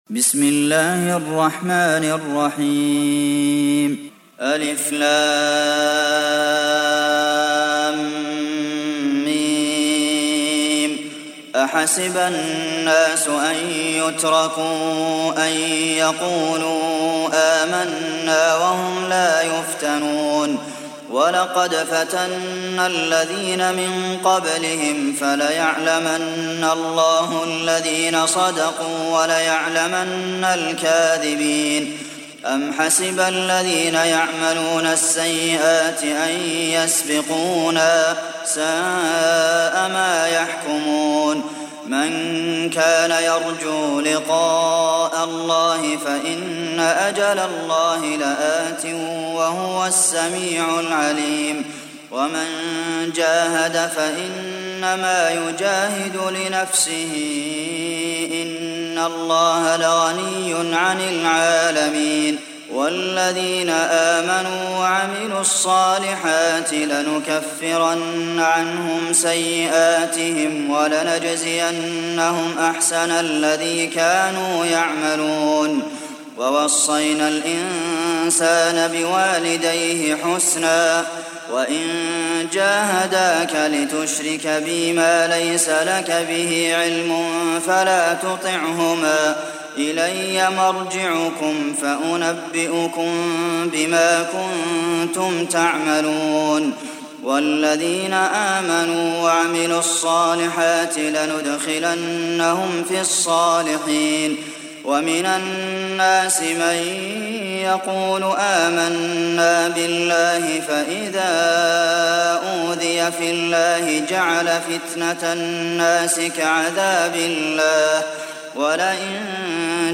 تحميل سورة العنكبوت mp3 بصوت عبد المحسن القاسم برواية حفص عن عاصم, تحميل استماع القرآن الكريم على الجوال mp3 كاملا بروابط مباشرة وسريعة